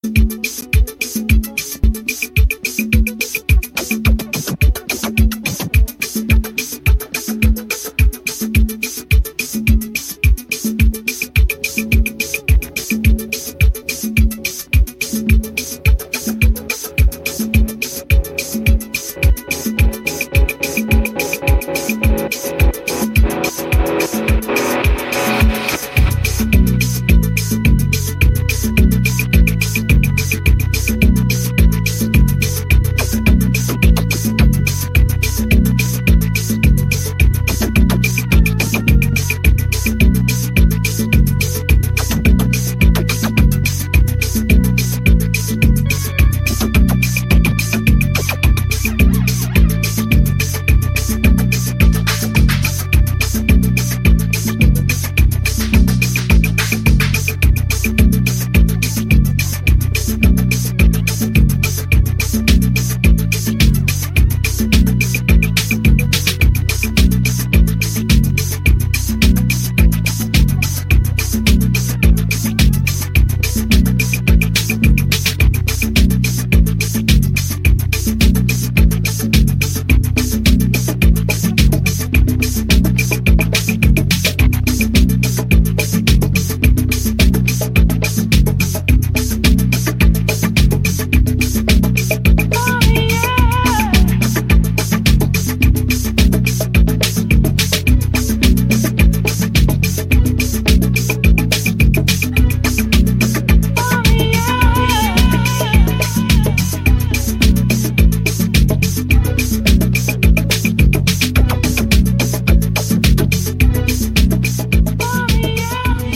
リズム・ボックスのビートに女性コーラスやシンセ・シーケンスが浮遊するトリップ感溢れる傑作ダブ〜アンビエント・ハウス